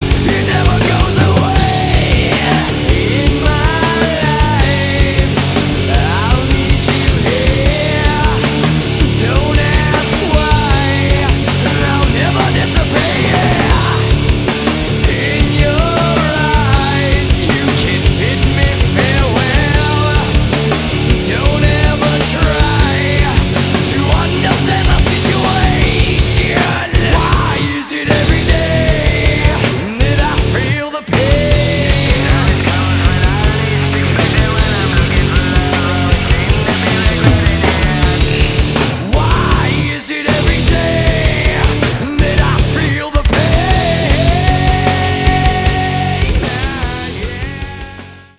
• Качество: 320, Stereo
мужской вокал
громкие
Alternative Metal
Hard rock